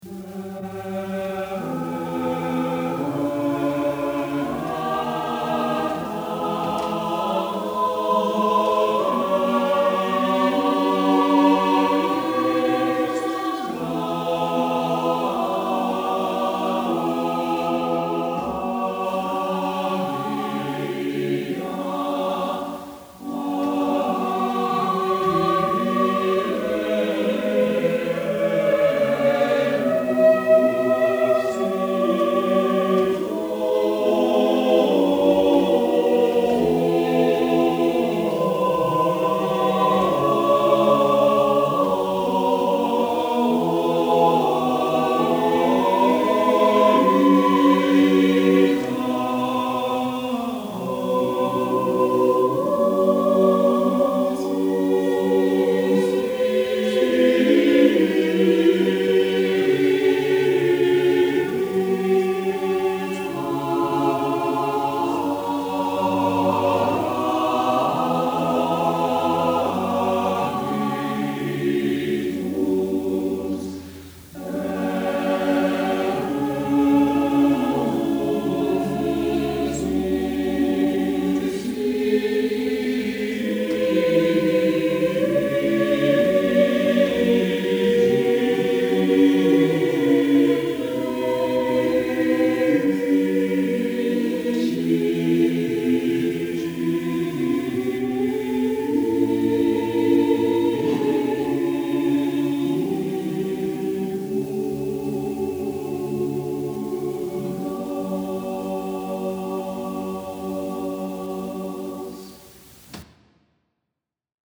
This polyphonic setting of a Pentecost hymn is from a Tarazona Cathedral manuscript, but the chant, in the soprano part, is not found in any surviving plainchant source.
We hear the first of seven stanzas.